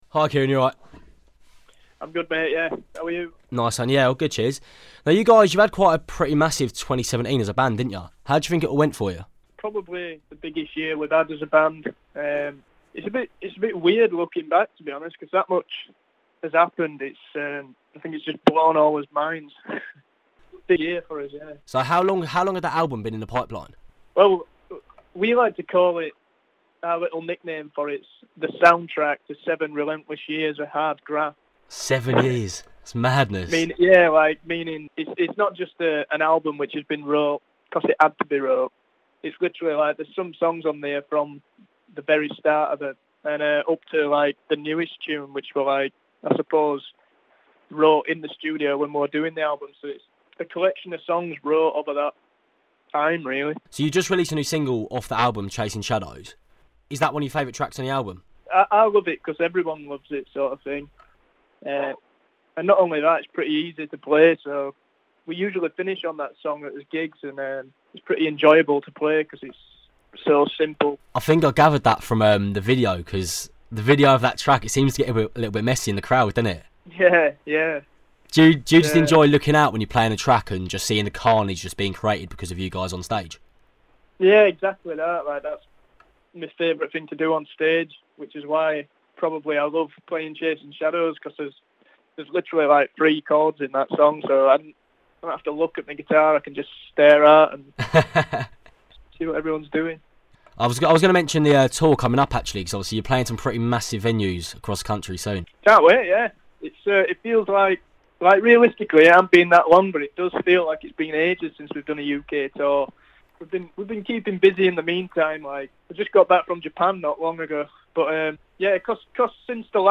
Interview: The Sherlocks